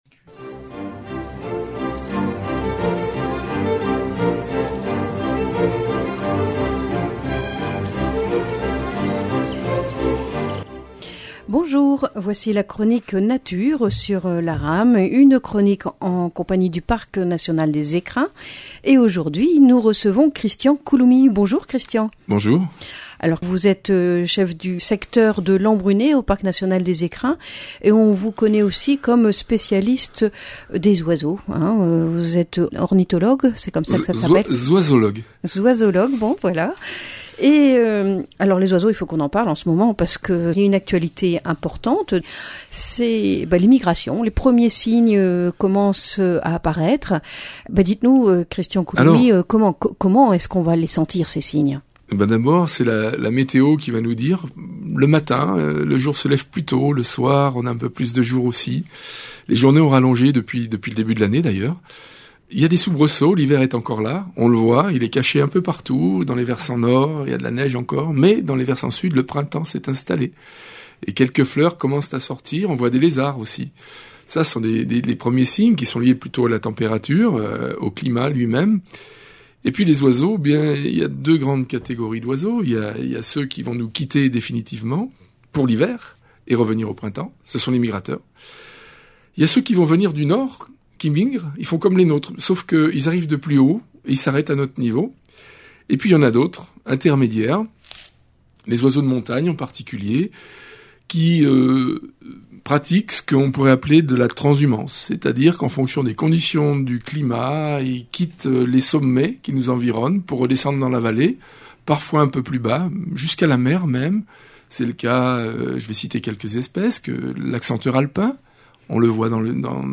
Chronique nature Ils migrent.